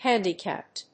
音節hánd・i・càpped 発音記号・読み方
/ˈhændiˌkæpt(米国英語), ˈhændi:ˌkæpt(英国英語)/